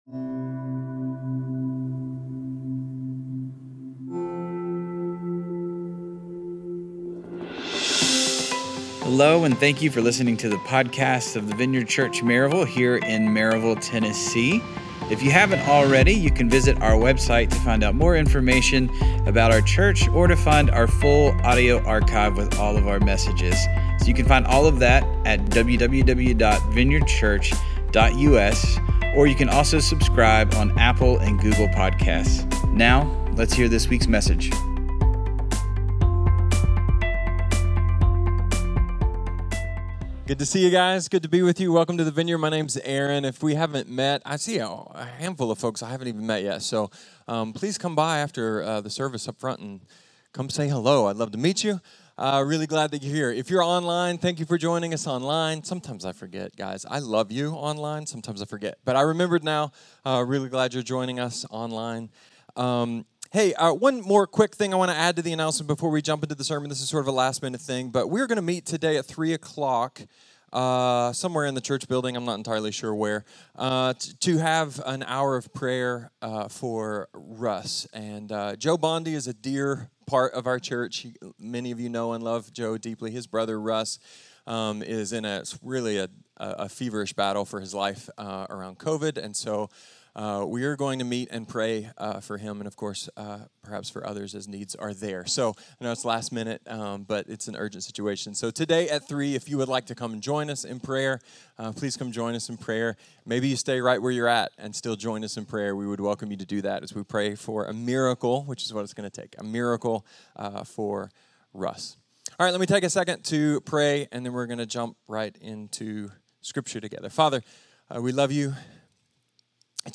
A message from the series "Habakkuk."